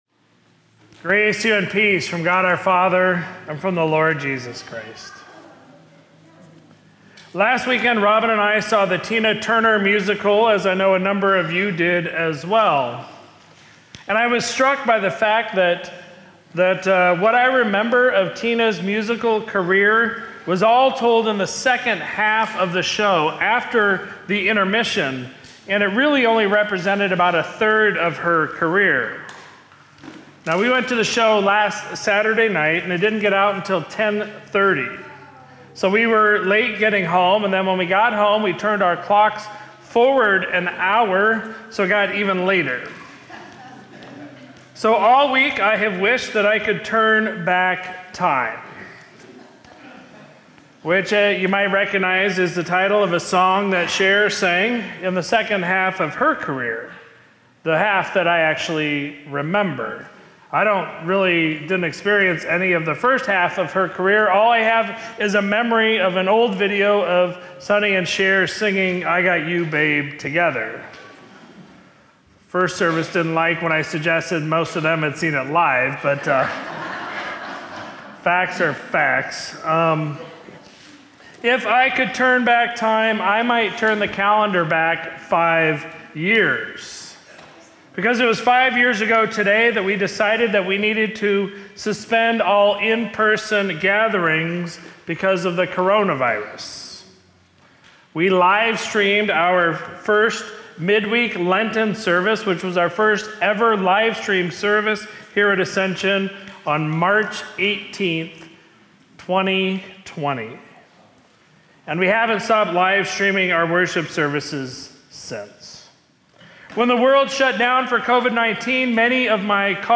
Sermon from Sunday, March 16, 2025